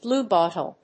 音節blúe・bòttle 発音記号・読み方
/ˈbluˌbɔtʌl(米国英語), ˈblu:ˌbɔ:tʌl(英国英語)/